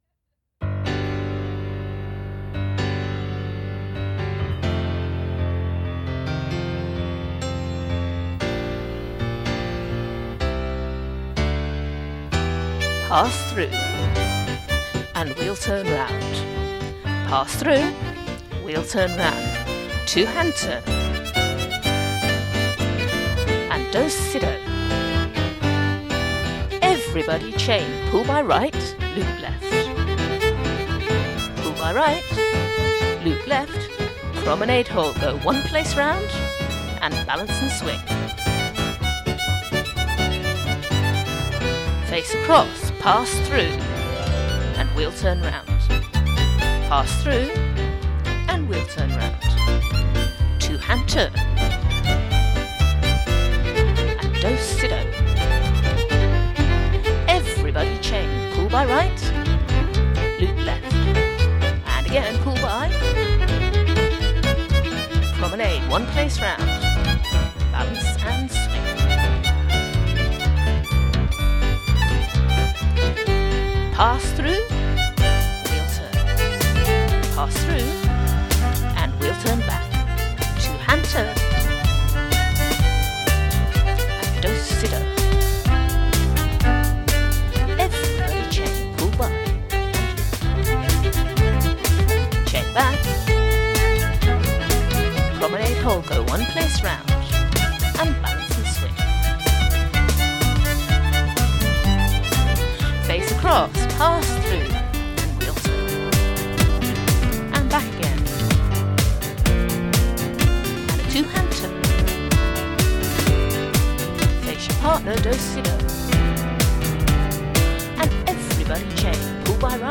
a suitably wacky version of the music